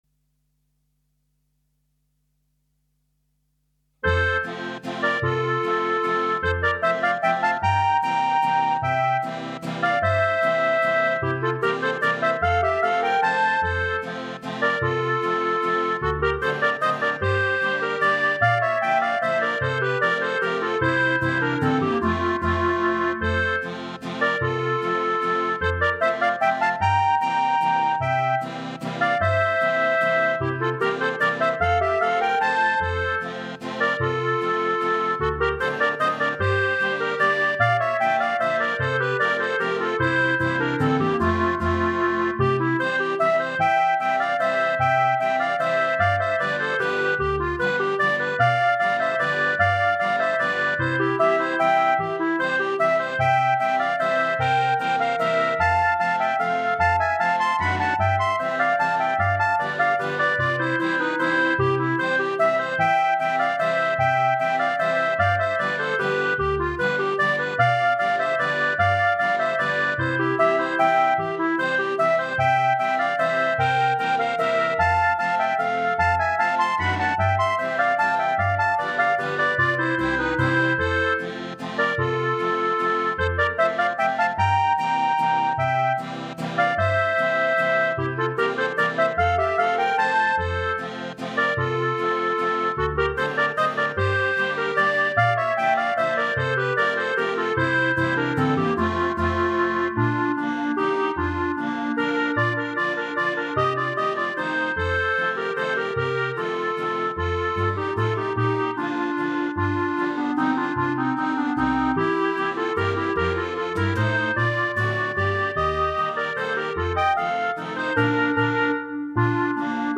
Instrumentation:2 Clarinet, Accordion
/ Keyboard Optional Bs Cl, Rhythm
In the style of a Ländler.